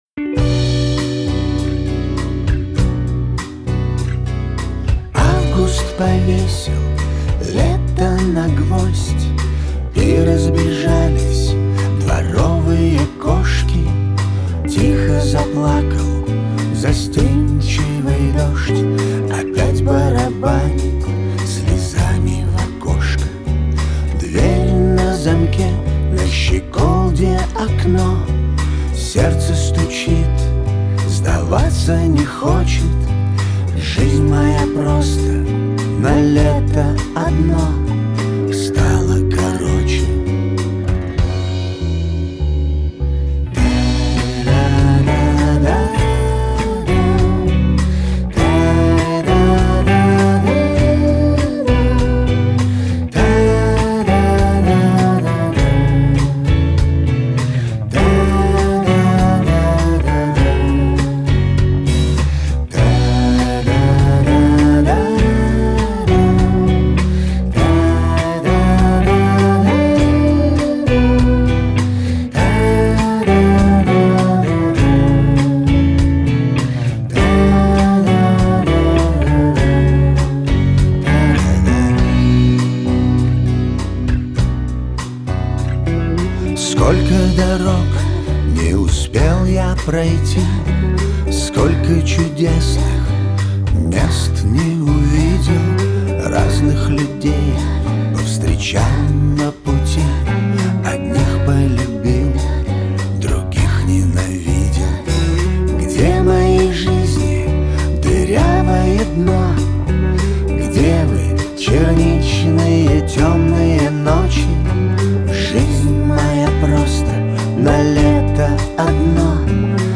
Жанр: Rock, Pop